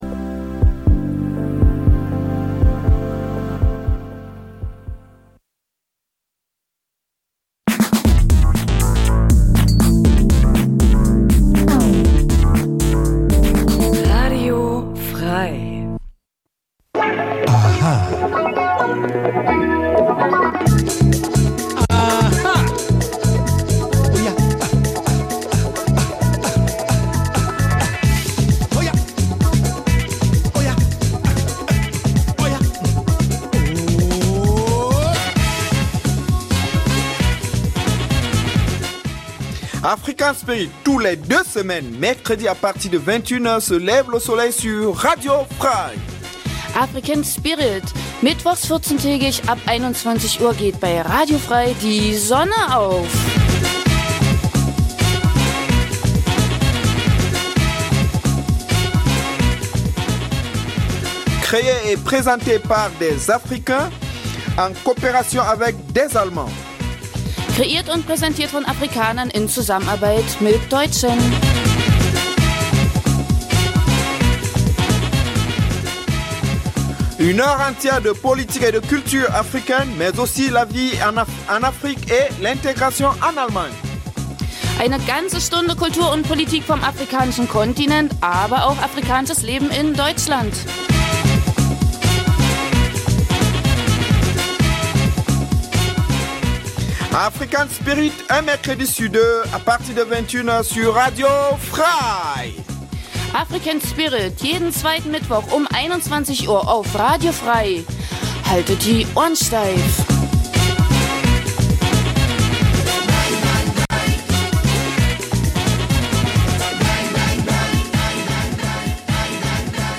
Afrikanisches zweisprachiges Magazin Dein Browser kann kein HTML5-Audio.
In einem Nachrichtenblock informiert die Sendung über die aktuelle politische Situation vor allem aus Afrikas Krisengebieten, aber auch über die Entwicklung der Zuwanderungs-politik in Deutschland welche das Leben vieler Afrikaner bei uns beeinflußt. Neben aktuellen Nachrichten gibt es regelmäßig Studiogäste, Menschen die in irgendeiner Form etwas mit Afrika zu tun haben: Zum Beispiel in Thüringen lebende Afrikaner, die uns über ihr Heimatland berichtet aber auch davon welche Beziehungen sie zu Deutschland haben oder Deutsche die aus beruflichen Gründen in Afrika waren und uns über ihre Erfahrungen berichten. Die Gespräche werden mit afrikanischer Musik begleitet.